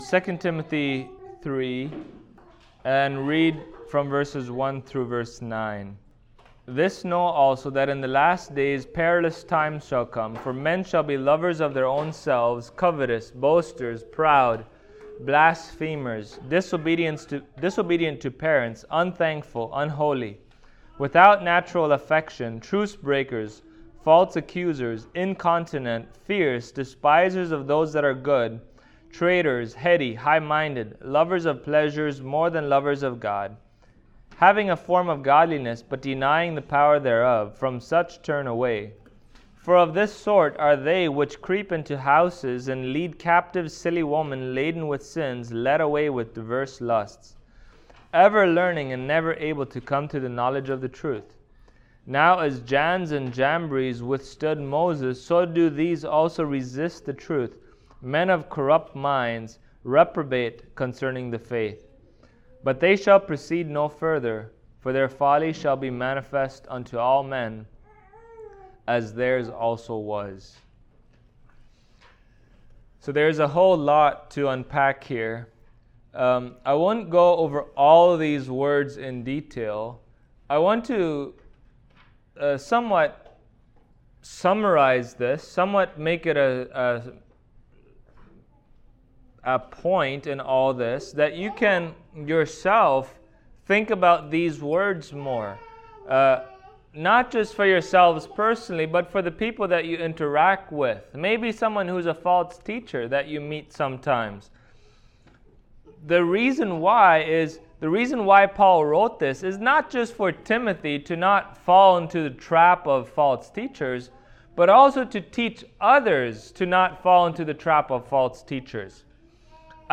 Service Type: Sunday Morning Topics: Eschatology , Last Days